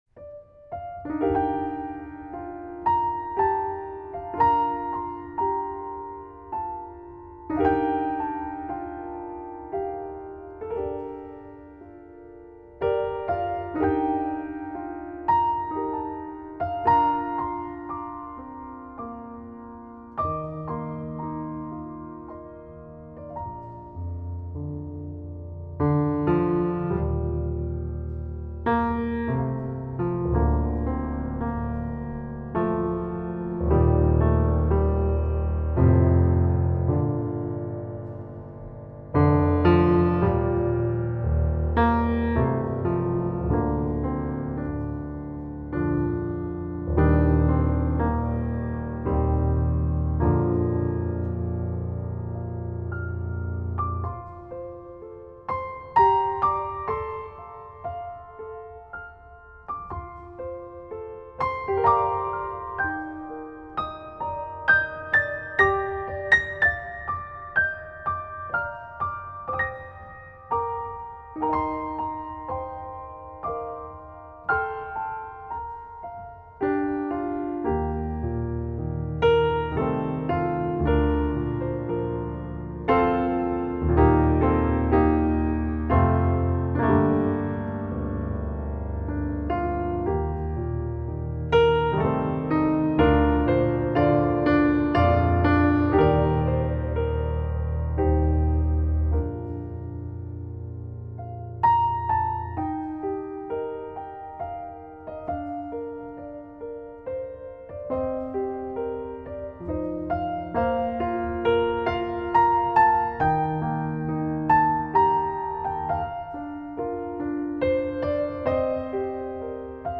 安定の３拍子、そして、朗読のような安心感がありながらも未来への期待の光を誘うメロディ運びが印象的です。
冒頭は低めの重厚感、中間部は弘大な開放感、そして後半部には更なる盛り上がりと余韻。
宇宙空間にふわっと漂うようなバーチャルさえ感じそうなスケールの大きな曲です。
・ゆったりした３拍子
・安定のリズムと規則正しい音並び